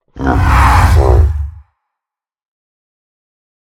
roar4.ogg